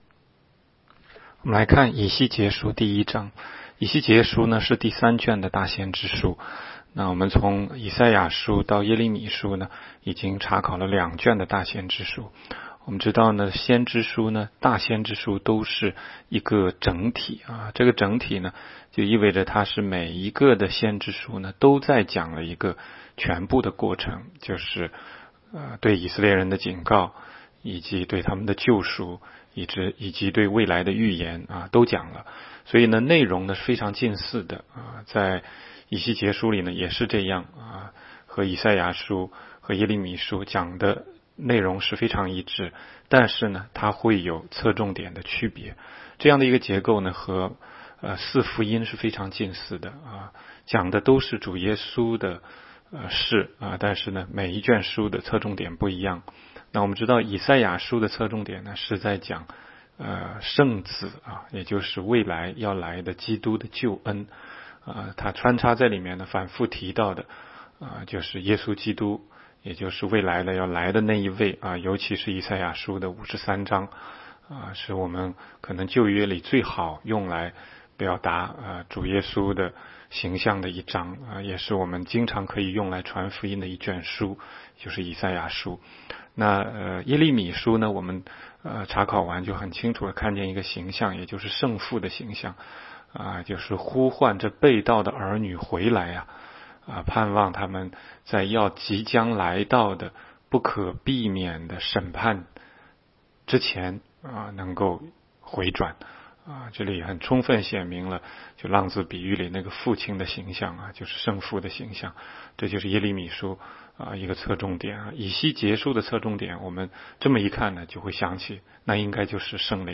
16街讲道录音 - 每日读经 -《以西结书》1章